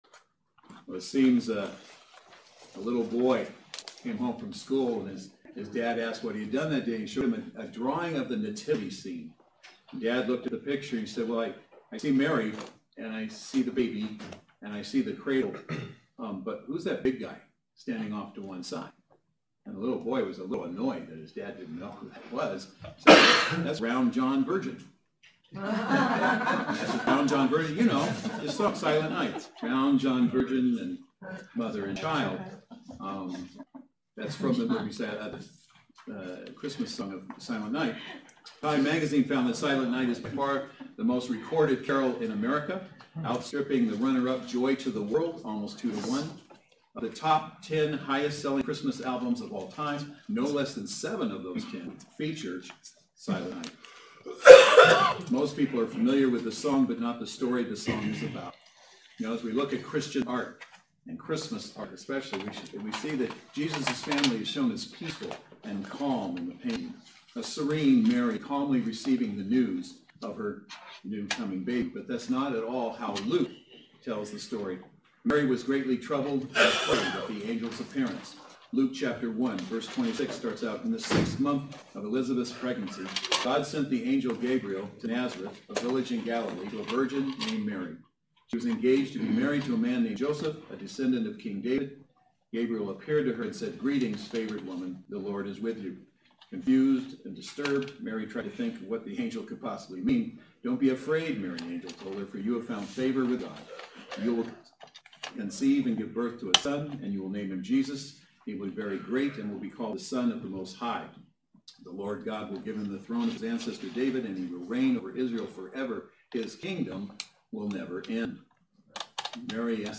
Series: Christmas Firsts Service Type: Saturday Worship Service Speaker